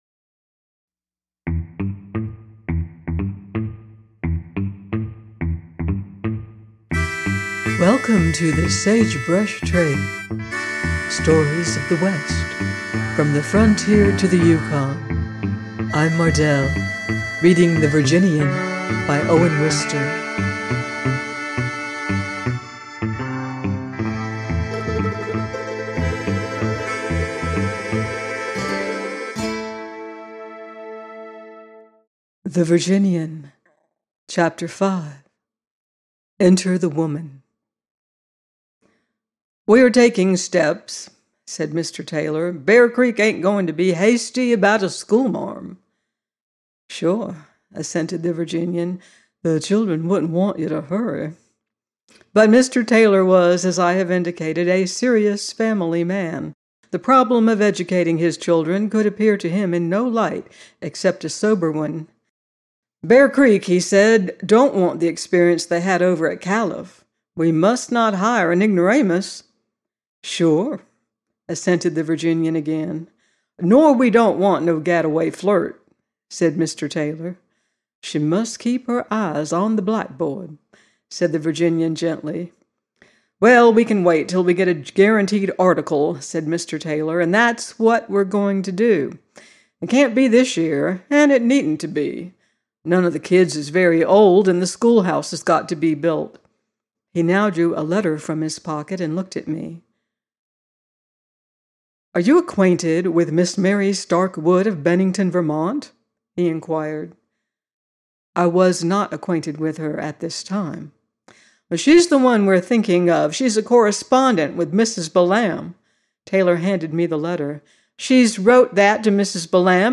The Virginian 05 - by Owen Wister - audiobook